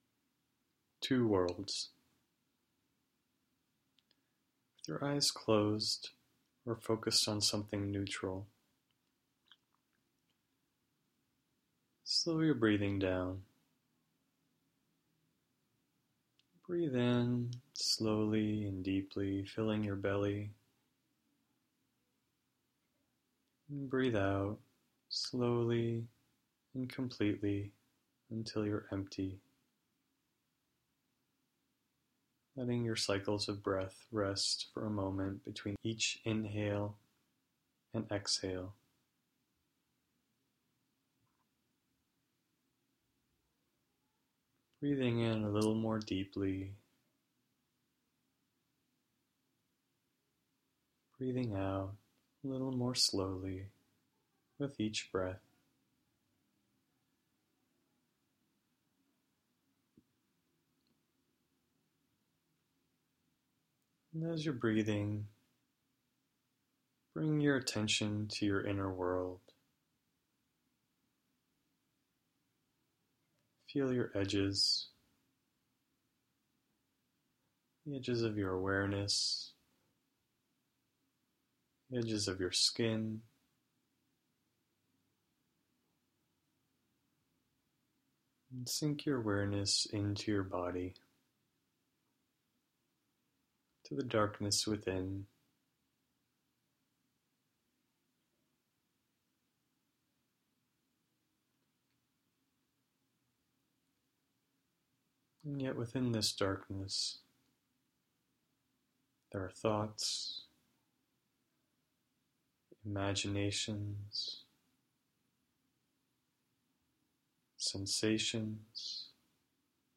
Meditation – The Two Worlds